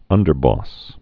(ŭndər-bôs, -bŏs)